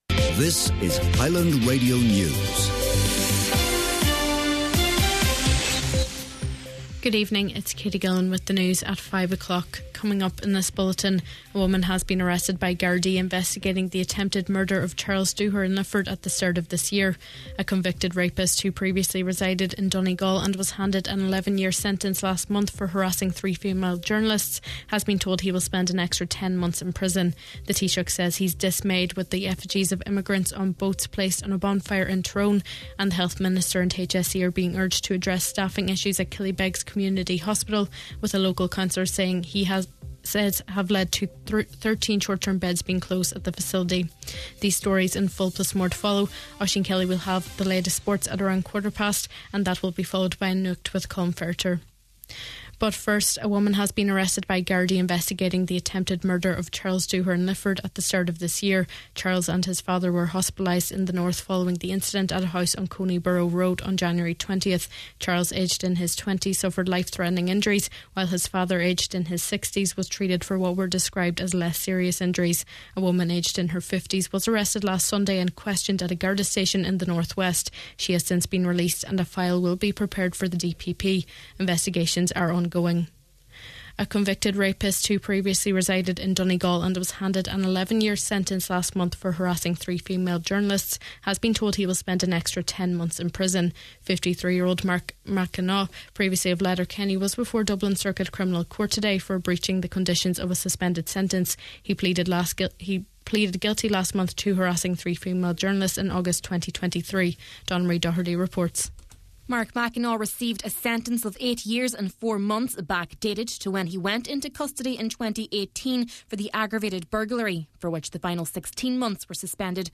Main Evening News, Sport, Nuacht and Obituaries – Friday July 11th